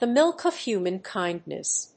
アクセントthe mílk of húman kíndness